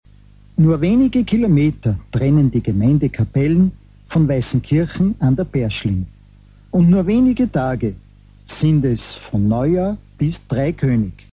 Hier befinden sich die MP3-Dateien, Mitschnitte aus der Radiosendung Aufhorchen in Niederösterreich vom Jänner 2008.
Ansage